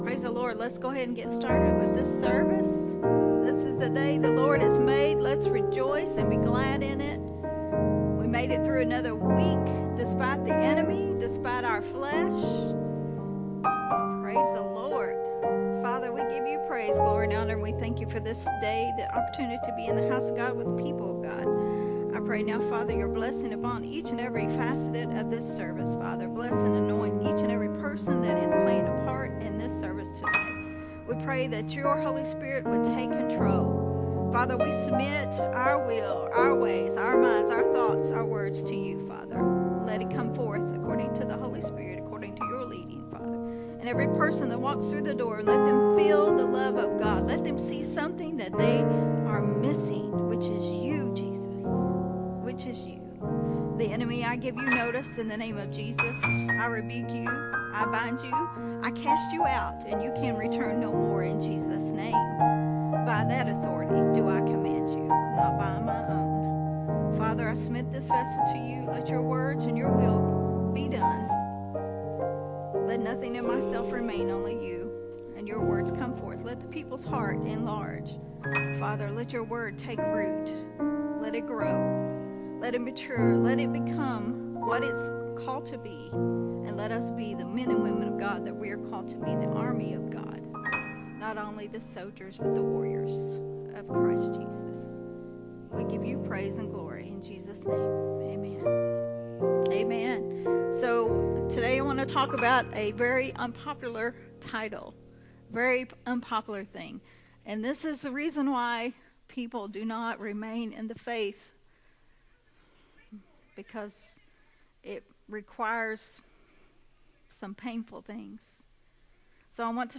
recorded at Unity Worship Center on March 19